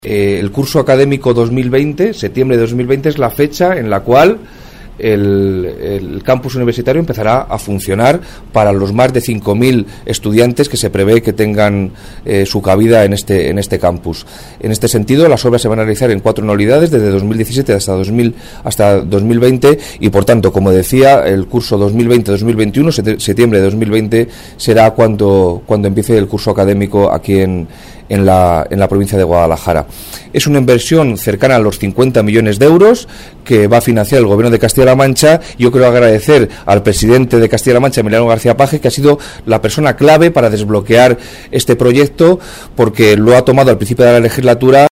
El delegado de la Junta en Guadalajara habla sobre el Campus Universitario de Guadalajara